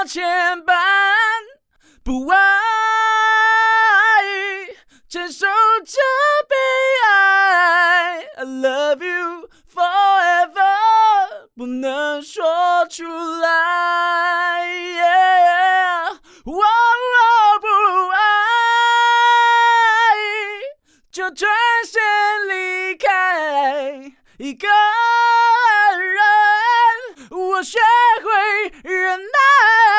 干声试听